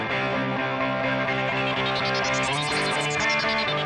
Synth1.wav